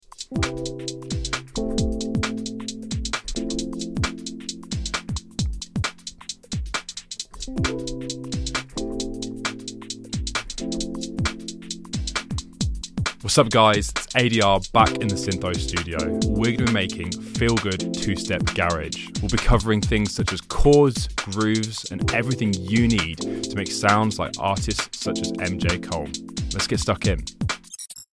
This time showing you how to create feel-good 2-step garage, perfect for the warm summer festival months. From crafting chords and grooves to building drums and capturing that MJ Cole style vibe, he covers everything you need to master this style.